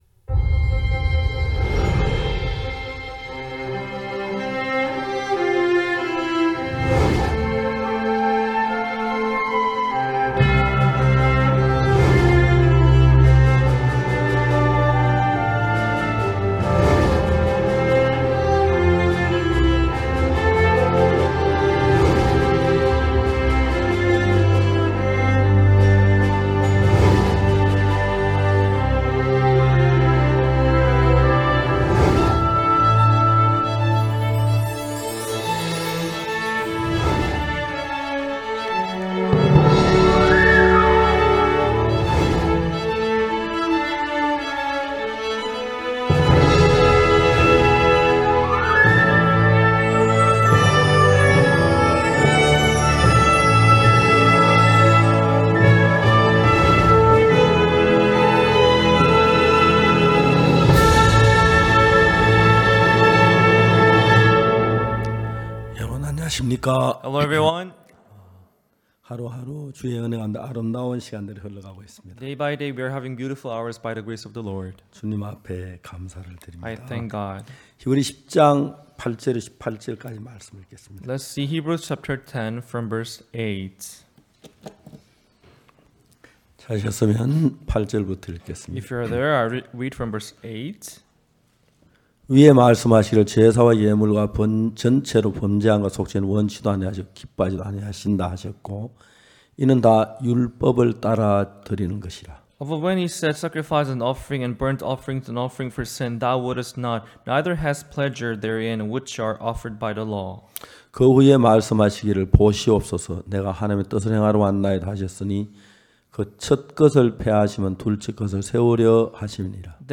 그 은혜로운 현장에서 울려퍼진 말씀들을 모았습니다.